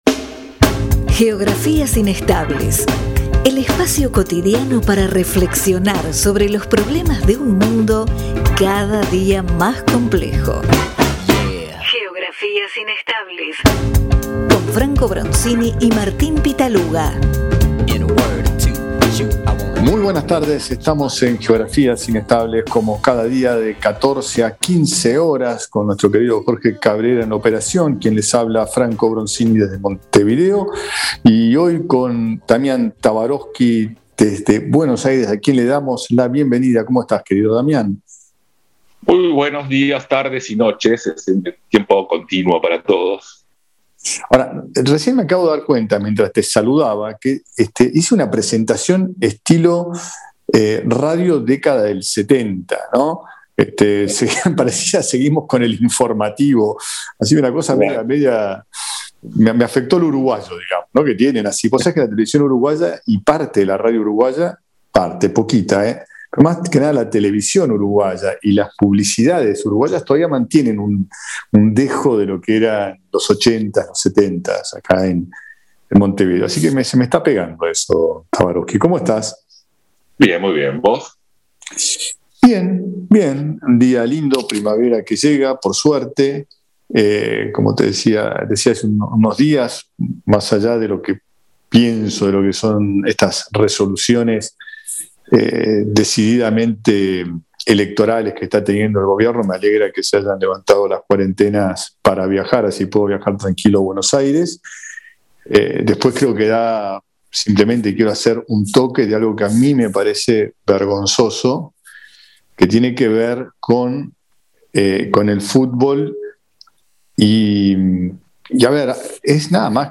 Columnista Invitado: Damián Tabarovsky